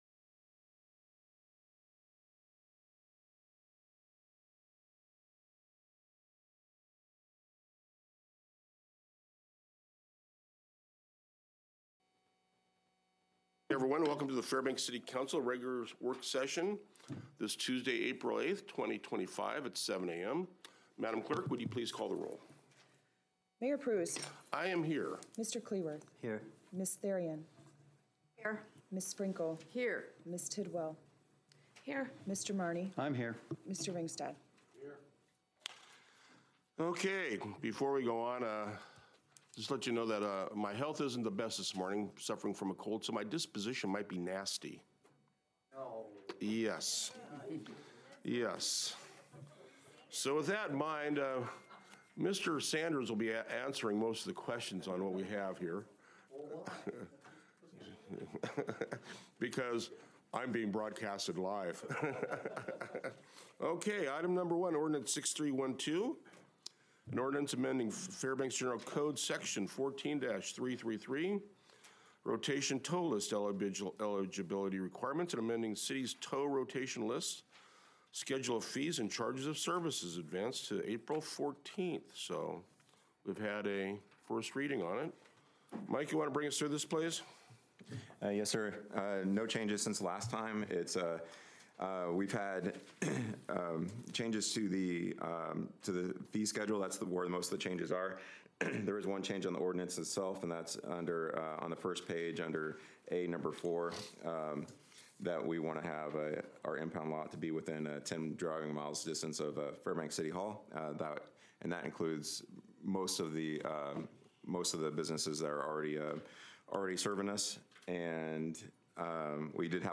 Regular City Council Work Session